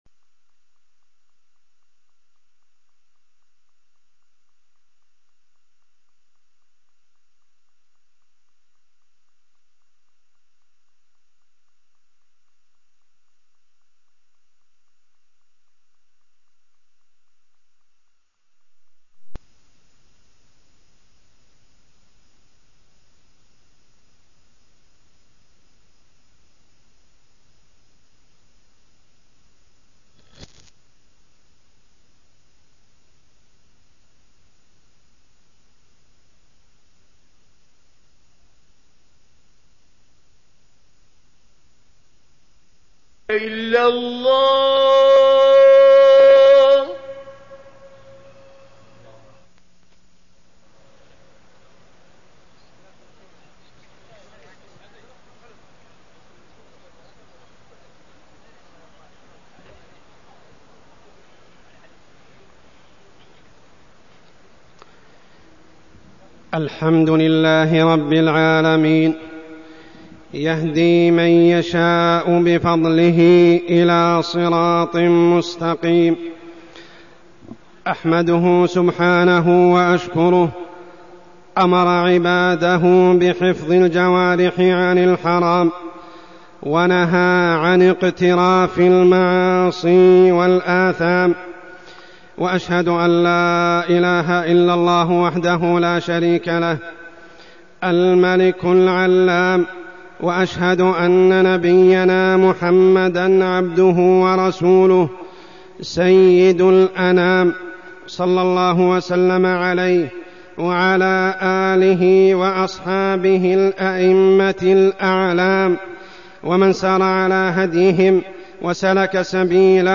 تاريخ النشر ١٨ جمادى الأولى ١٤١٨ هـ المكان: المسجد الحرام الشيخ: عمر السبيل عمر السبيل الغيبة